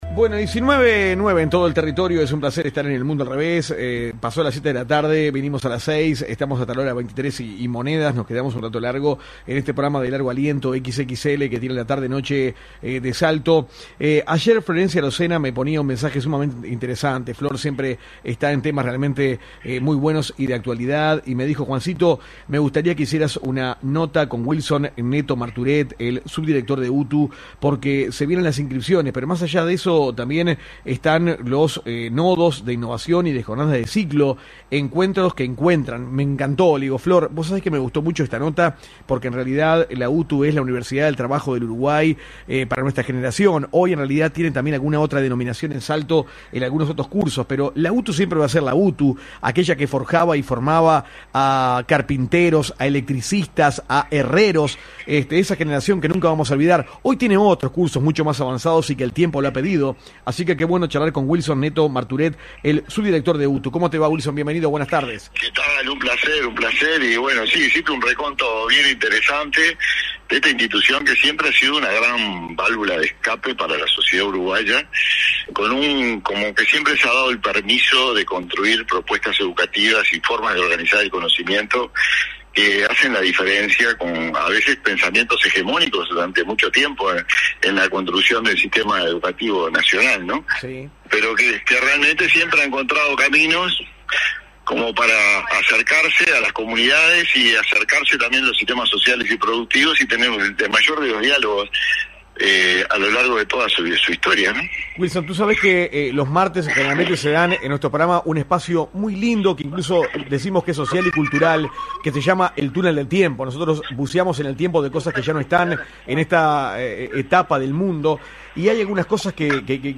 Dialogamos telefónicamente con Subdirector de UTU Wilson Netto